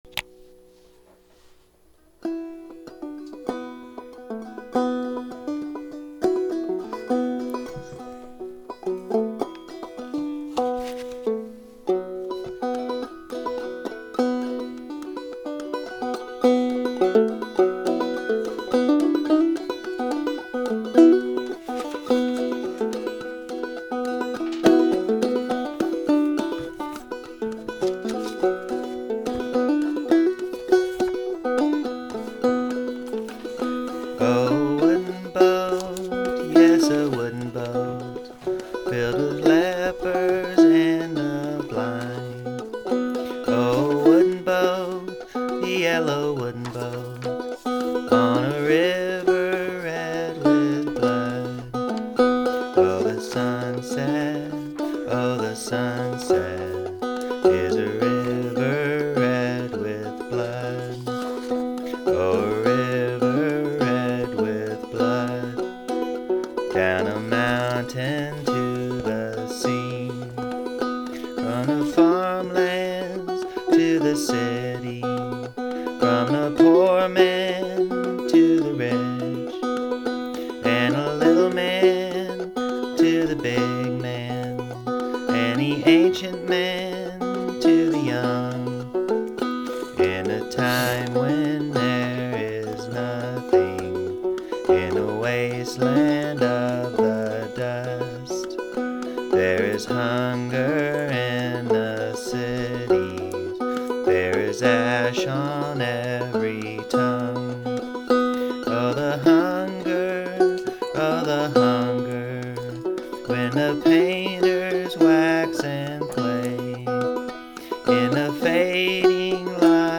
song: The Yellow Boat, banjo and voice